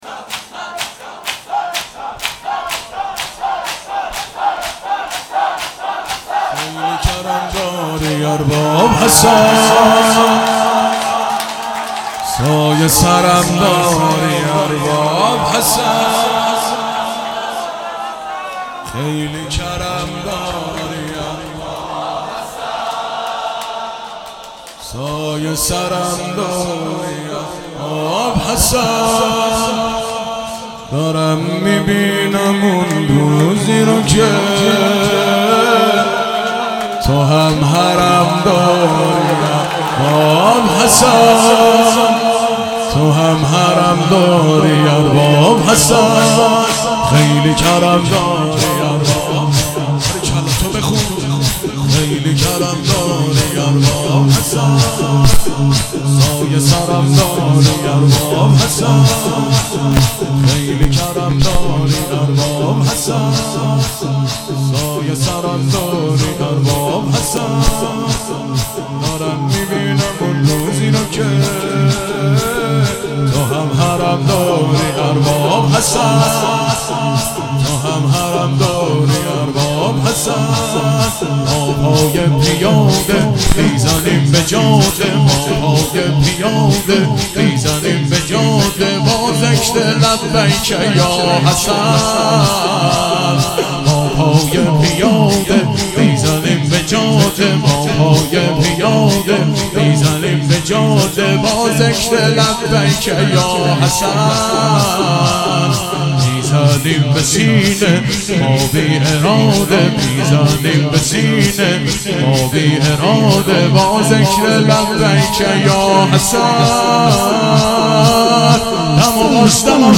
شب شهادت امام حسن (ع)- شور - خیلی کرم داری ارباب حسن